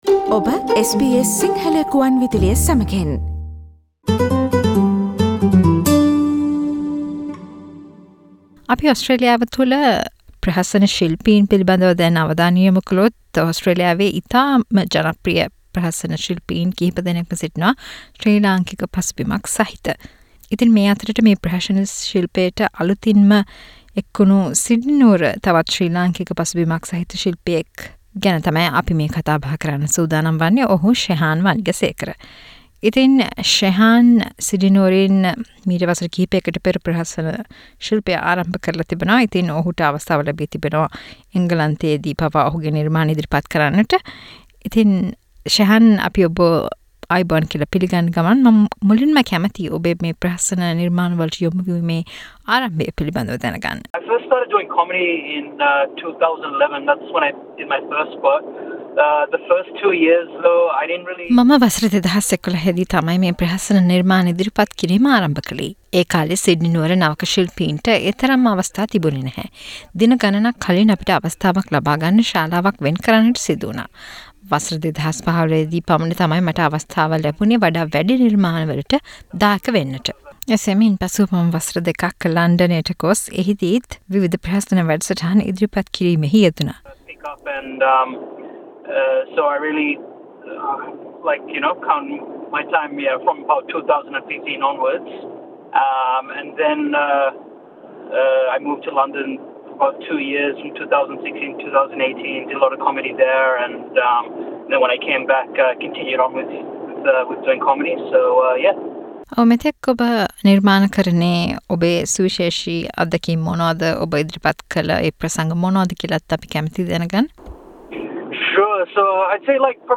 SBS Sinhala Radio interviewed